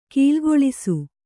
♪ kīlgoḷisu